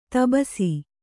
♪ tabasi